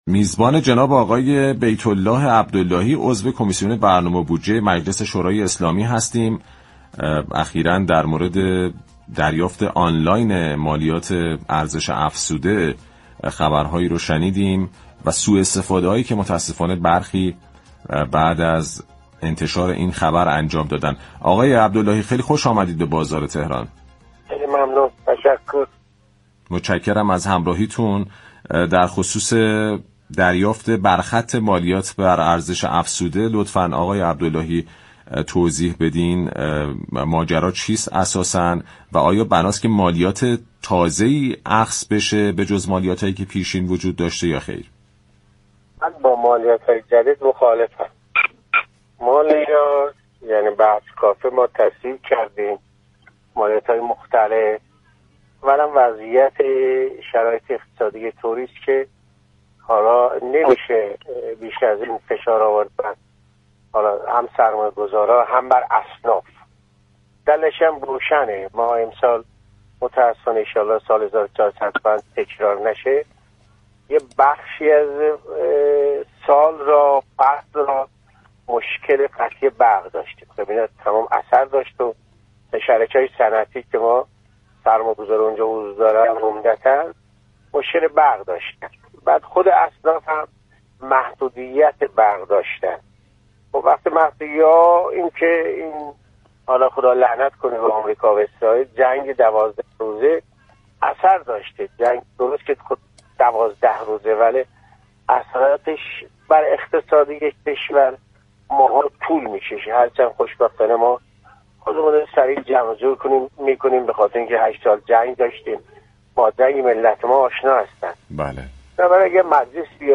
بیت‌الله عبداللهی، عضو كمیسیون برنامه و بودجه مجلس شورای اسلامی، در گفت‌وگو با رادیو تهران تأكید كرد كه اجرای دریافت برخط مالیات بر ارزش افزوده به‌معنای وضع مالیات جدید نیست و مجلس در شرایط فعلی اقتصادی، با هرگونه افزایش نرخ یا فشار مالیاتی بر مردم و اصناف مخالف است.